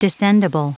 Prononciation du mot descendible en anglais (fichier audio)
Prononciation du mot : descendible